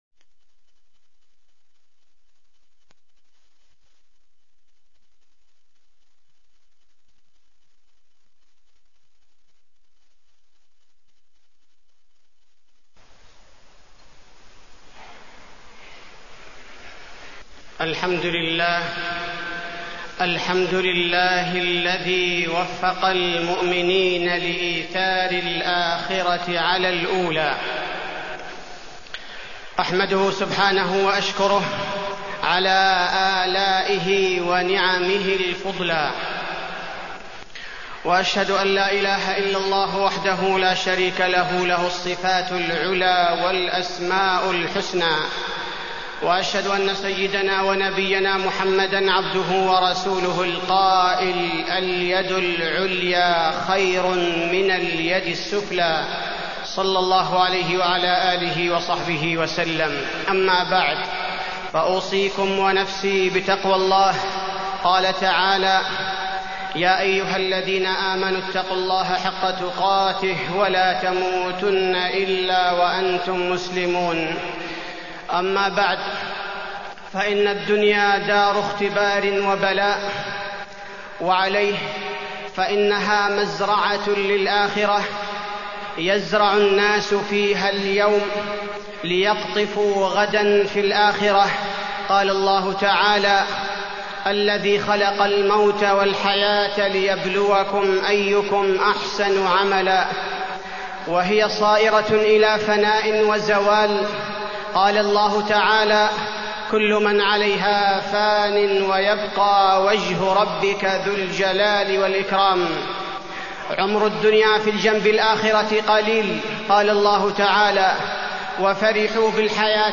تاريخ النشر ٢١ شعبان ١٤٢٤ هـ المكان: المسجد النبوي الشيخ: فضيلة الشيخ عبدالباري الثبيتي فضيلة الشيخ عبدالباري الثبيتي وصف الحياة الدنيا